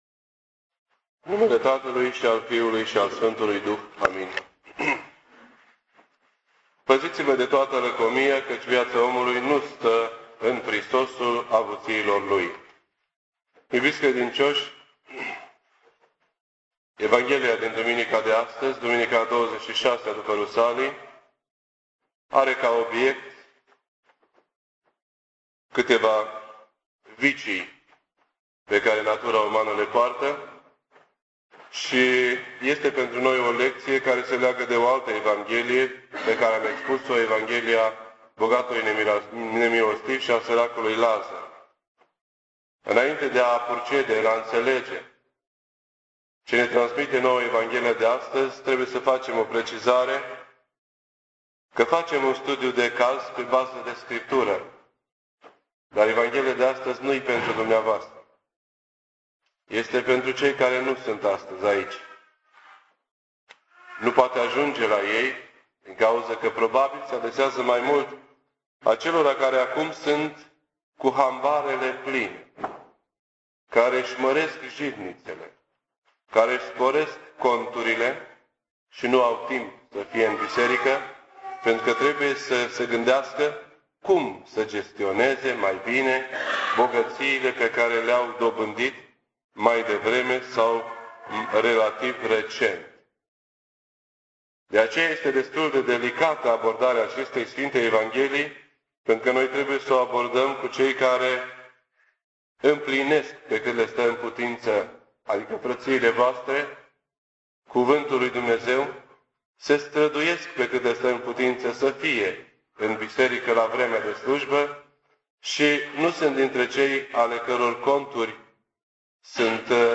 This entry was posted on Sunday, November 18th, 2007 at 10:02 AM and is filed under Predici ortodoxe in format audio.